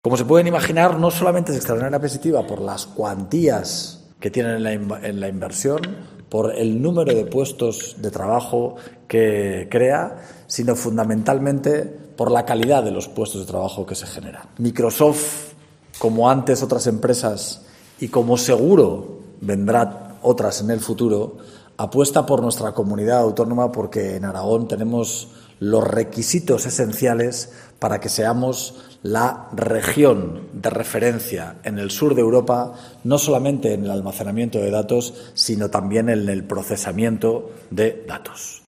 El presidente Azcón valora el anuncio de Microsoft de crear un campus de centros de datos en Aragón.